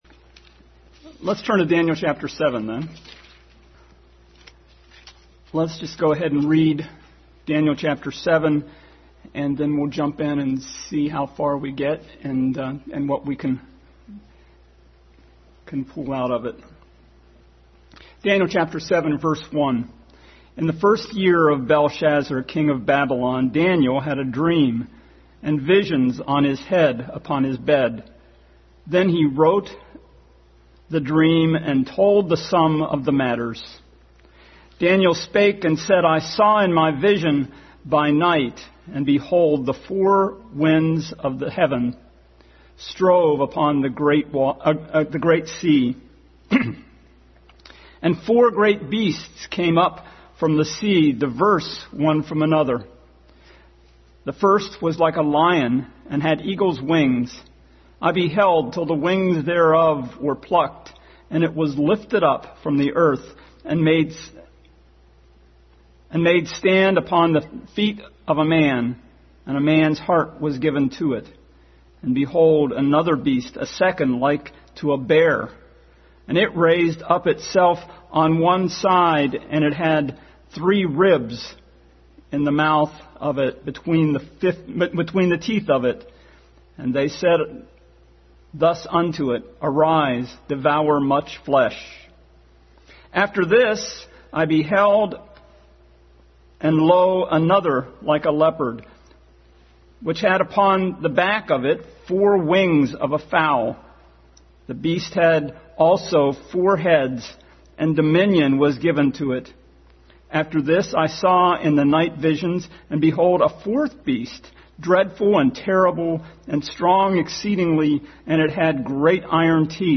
Daniel 7:1-5 Passage: Daniel 7:1-5, 2 Peter 3:7-13 Service Type: Sunday School